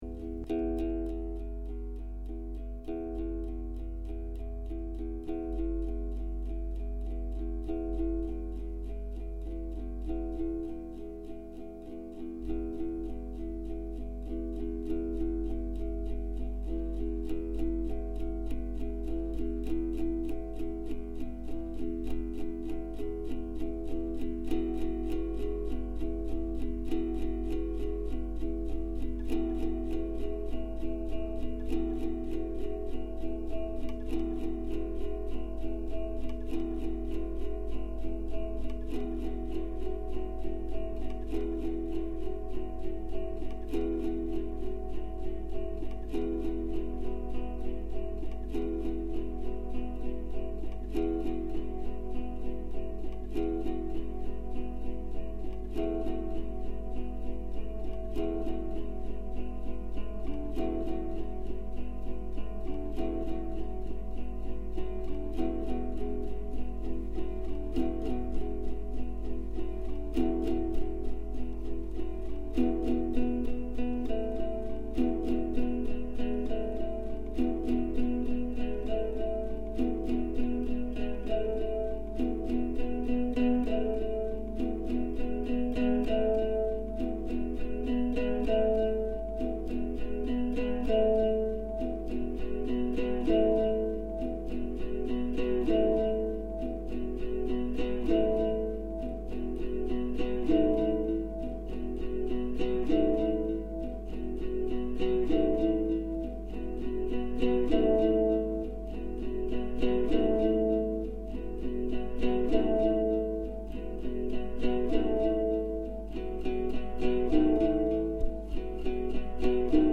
220v ungrounded hum
day 16 after dinner guests first track part 2. with the borrowed nylon stringed, semi-acoustic guitar with a transducer pickup attached, plugged into the echo pedal which was powered from the wall as was the laptop, which helped make the low hum.